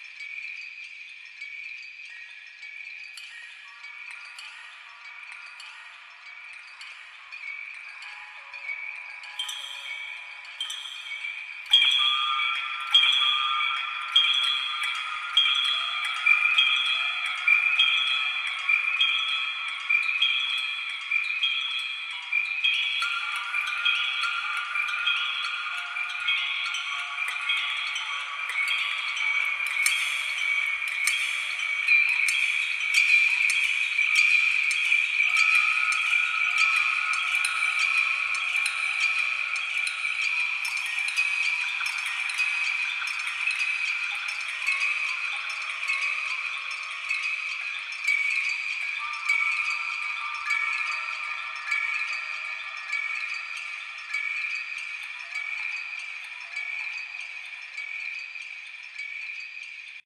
cave_chimes.ogg